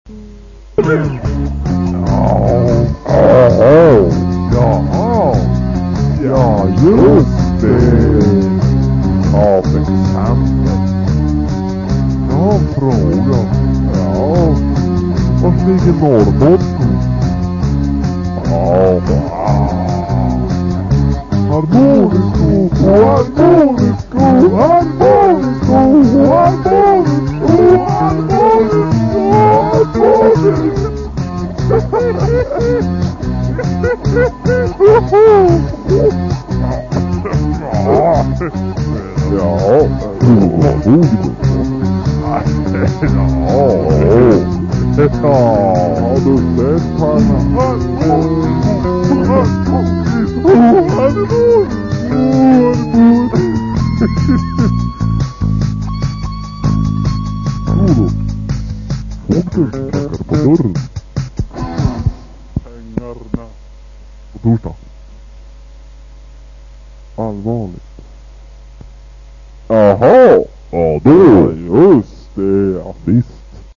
Guitars, Vocals
Drums, Vocals, Bass